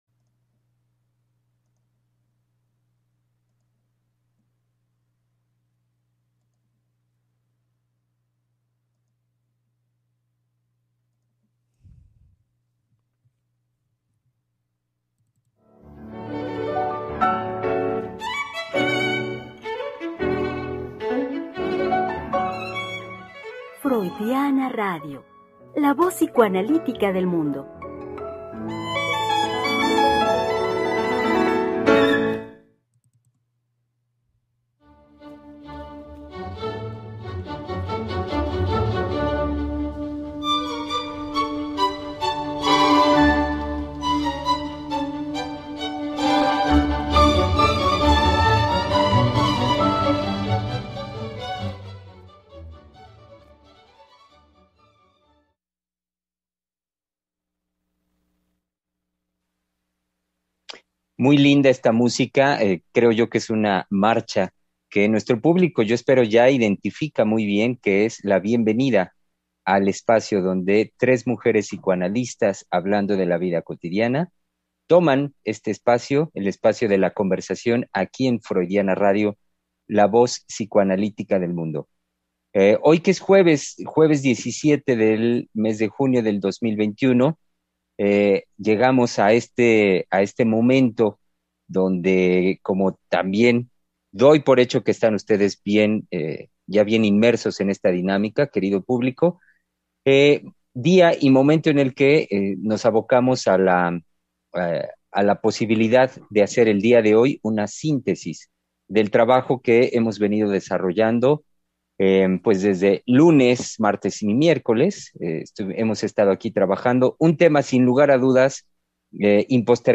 Programa trasmitido el 17 de junio del 2021.